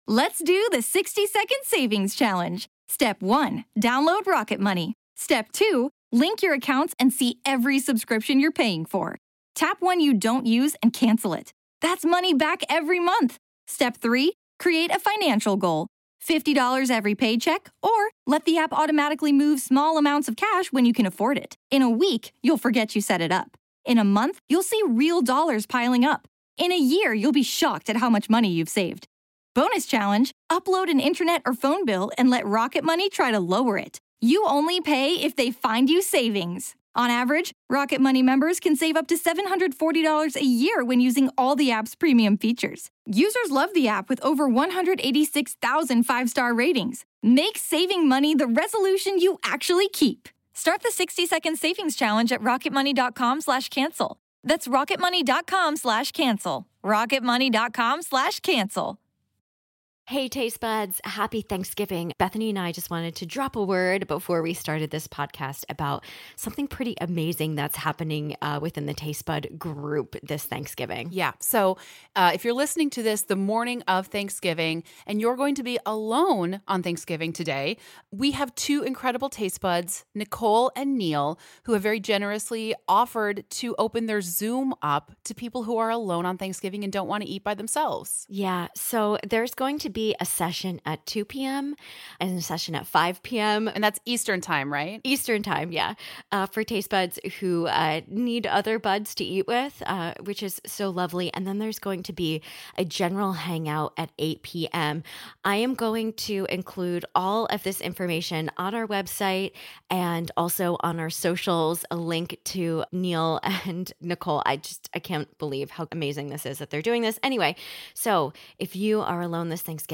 Then, the show just devolves into chaos: there is singing, bad Turkey jokes, strange holiday gift ideas, Thanksgiving conversation starters, and the entire show must stop down for a bathroom emergency.